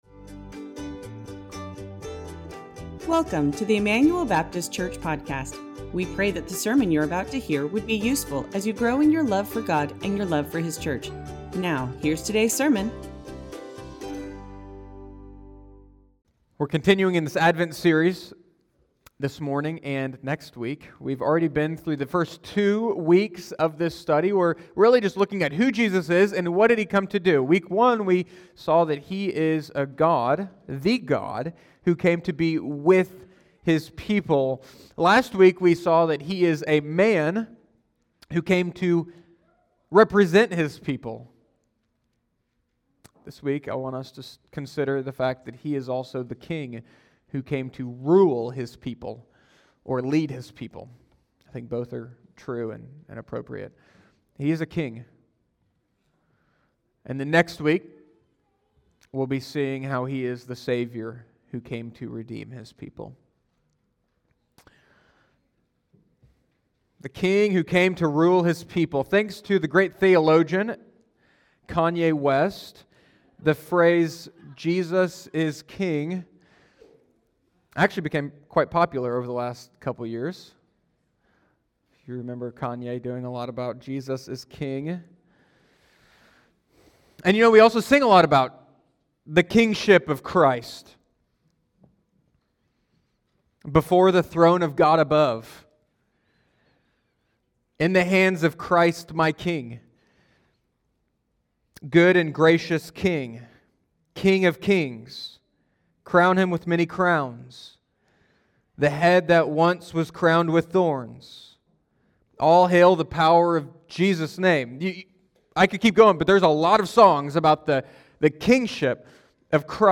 Audio Sermon Archive